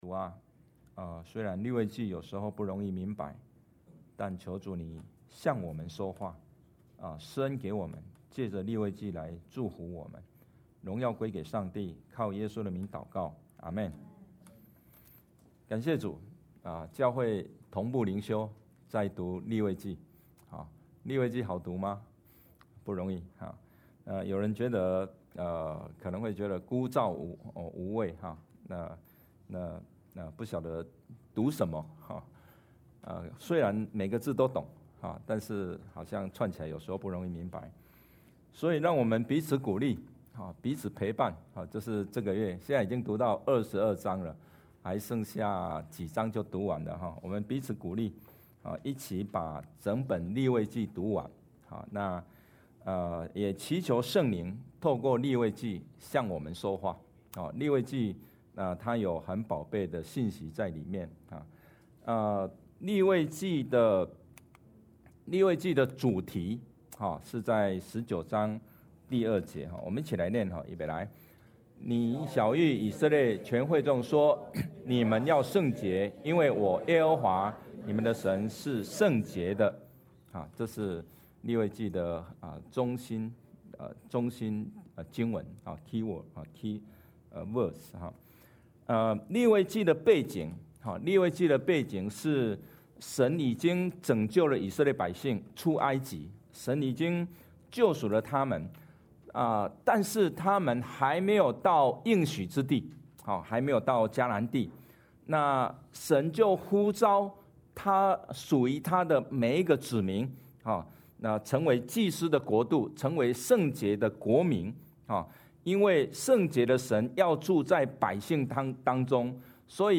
主日证道 Download Files Notes « 作贵重的器皿 感恩的家人 » Submit a Comment Cancel reply Your email address will not be published.